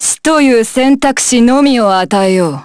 Lucikiel_L-Vox_Skill6_jp.wav